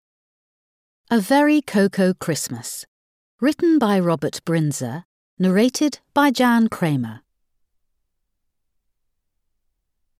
Audio knihaA Very Coco Christmas
Ukázka z knihy